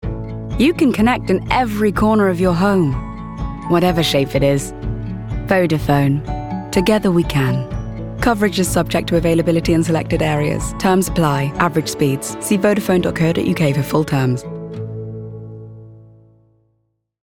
RP
Female
Husky
Warm
VODAPHONE COMMERCIAL